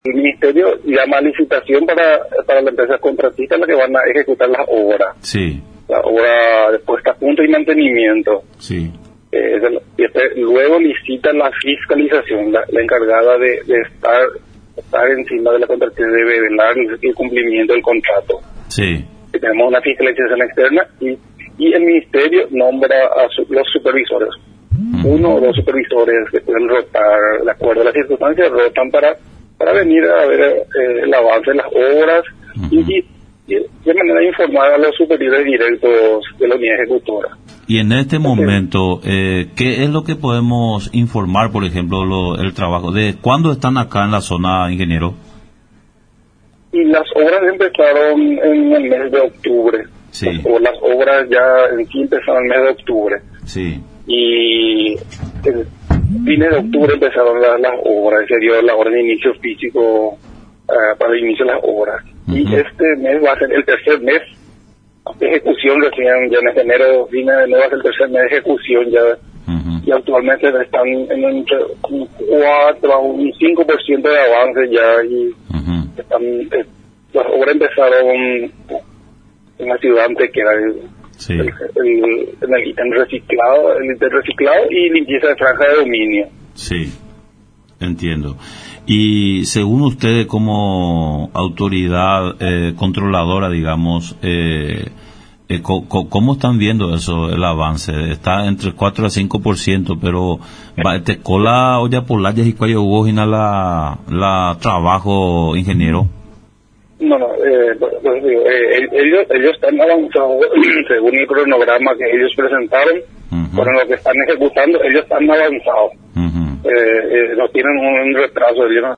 en conversación con Radio Nacional